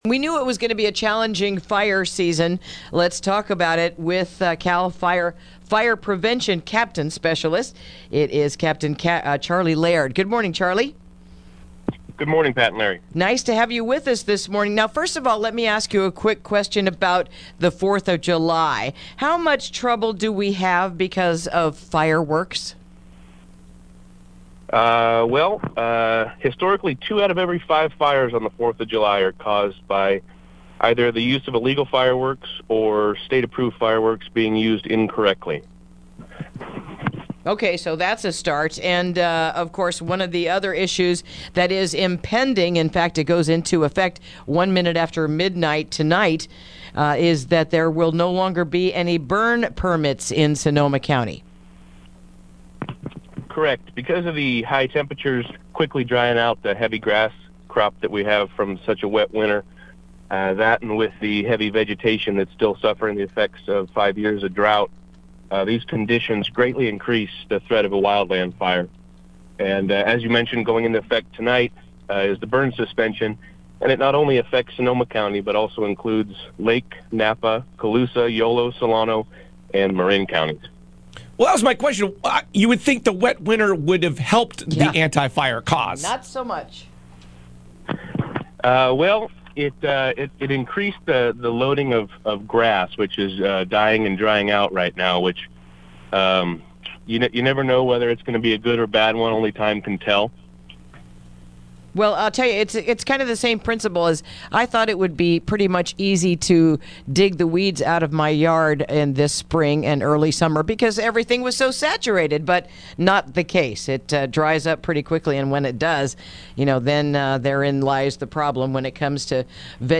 Interview: Burn Suspension Taking Place this Weekend